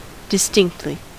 Ääntäminen
IPA: /'dɔɪ̯.tlɪç/